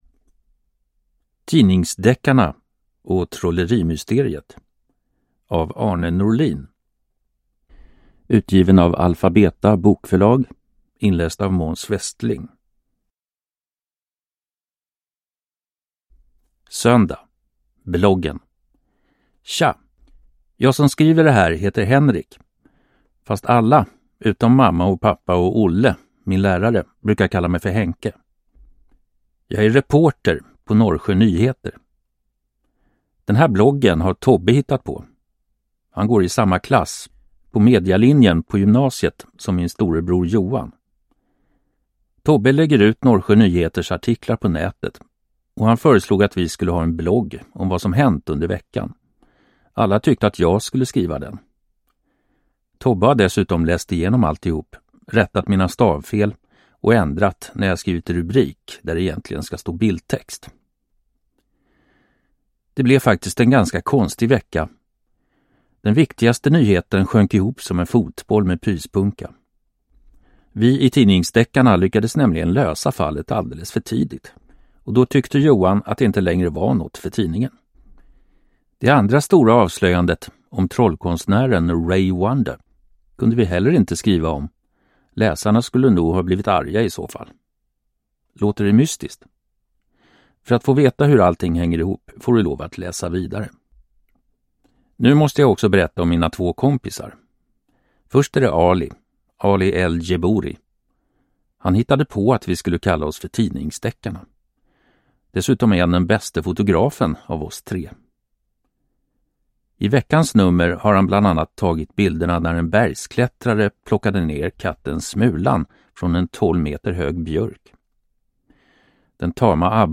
Tidningsdeckarna och trollerimysteriet – Ljudbok